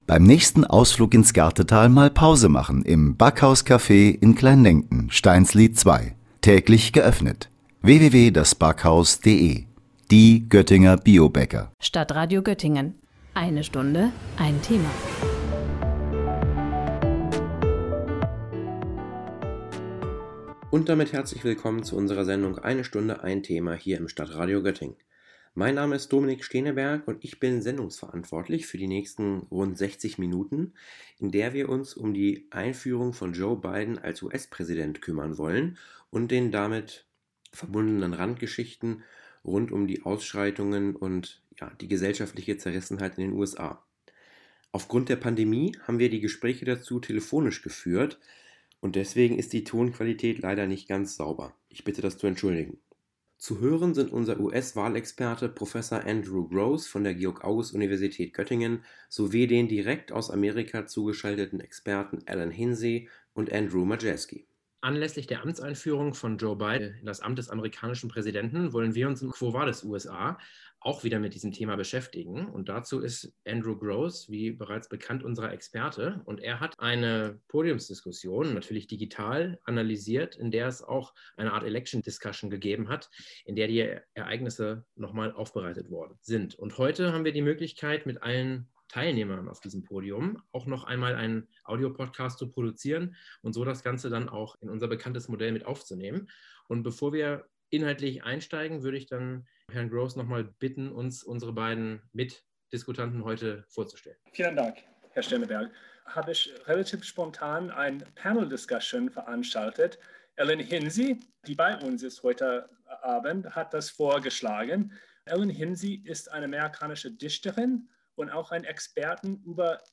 Die USA nach der Amtseinführung von Joe Biden – ein Expertengespräch